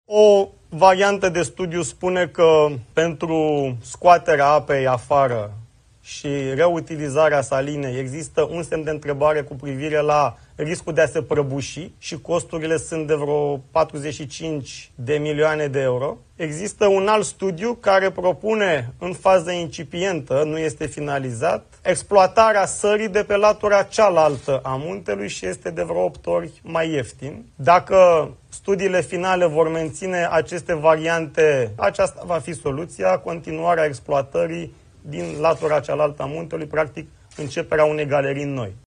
Riscurile de inundare a Salinei Praid erau cunoscute încă din 2006, dar instituțiile statului și-au pasat răspunderea, a mai declarat, într-o conferință de presă, Radu Miruță.